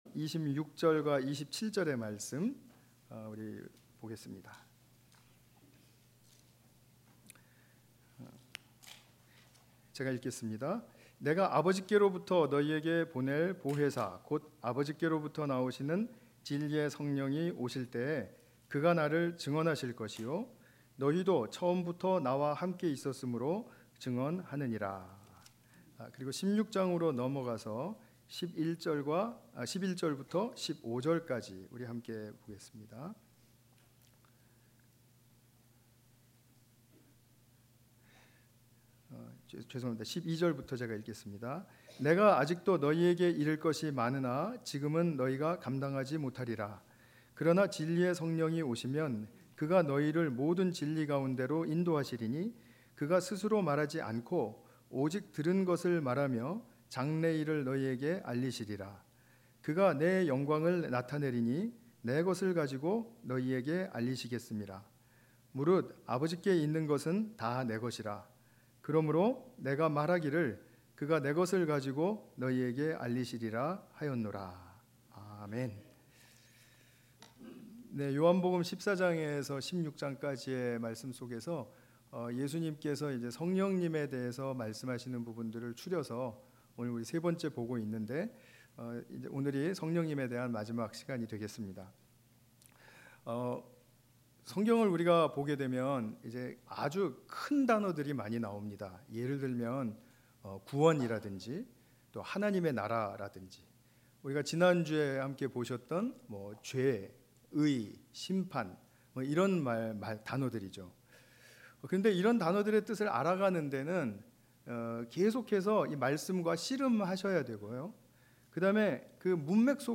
주일예배